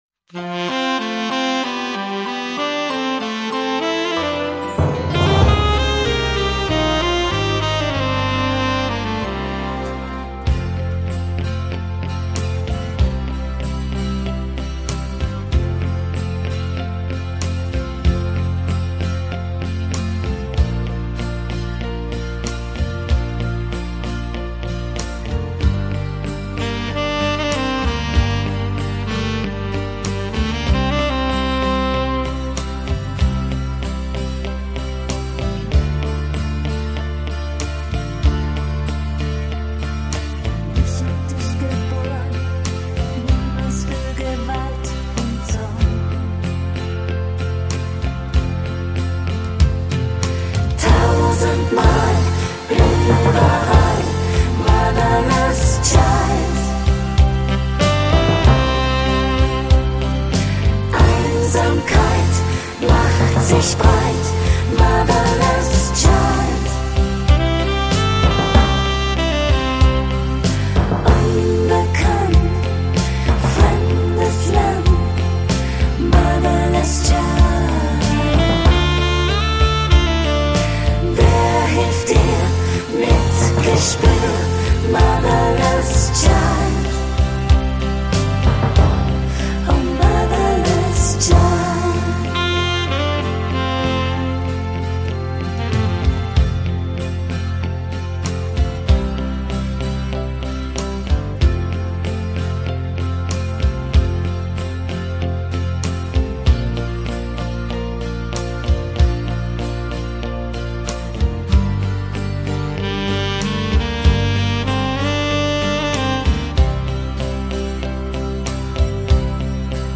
deutsche Version: mp3 Lyrics Deutsch Playback mit Chor
Ballade